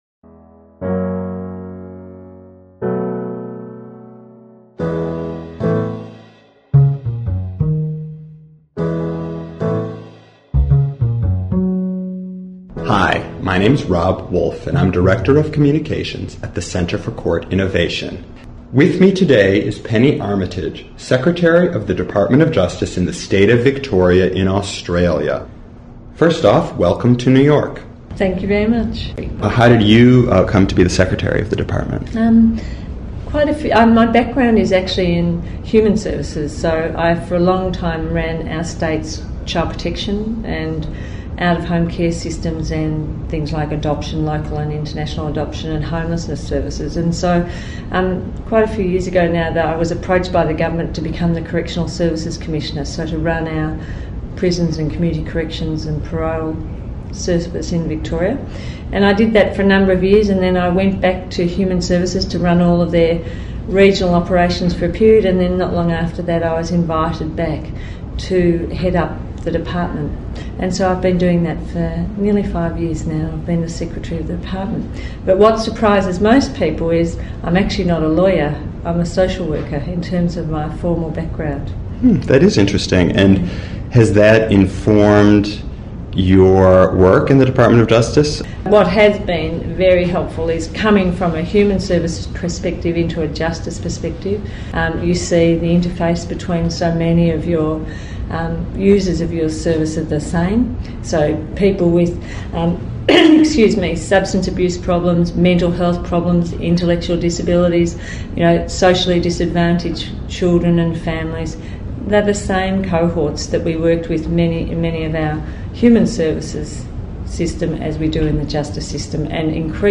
Australia's First Community Court: A Conversation with Penny Armytage - Center for Justice Innovation